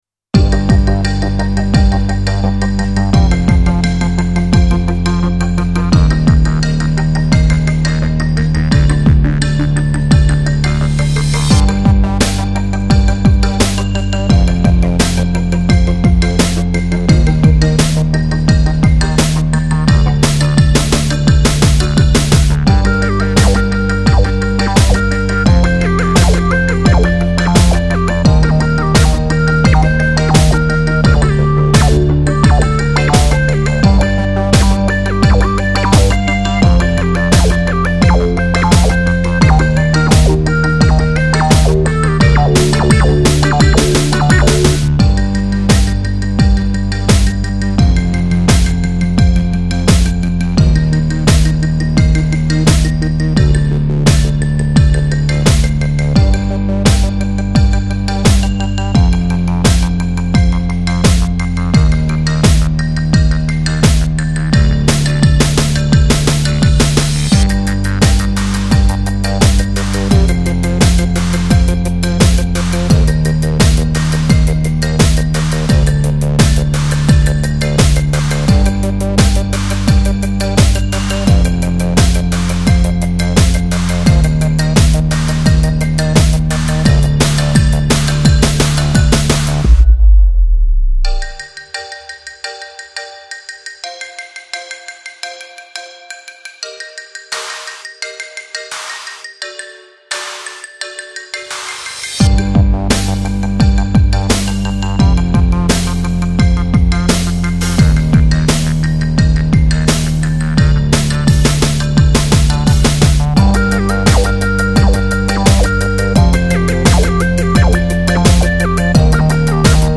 Жанр: Chillout, Lounge, Downtempo, Ambient